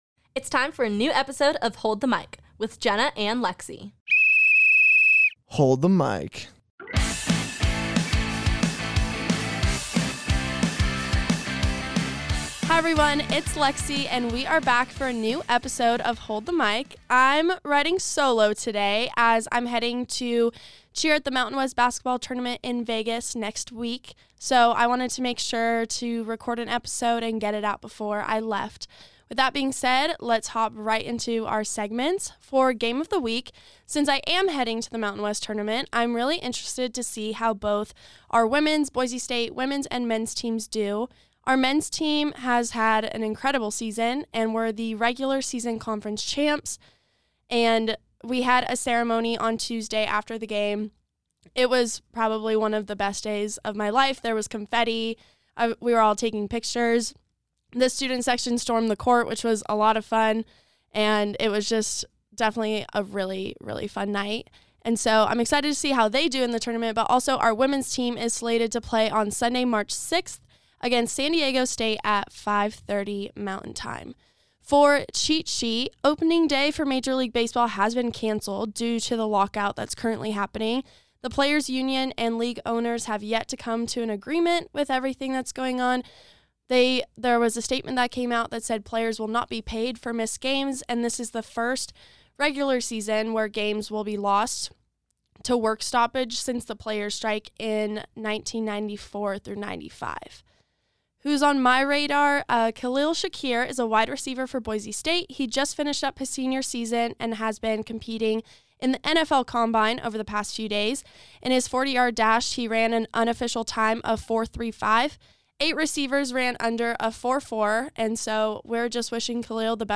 interviews hometown friend and beach volleyball player